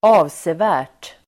Uttal: [²'a:vse:vä:r_t]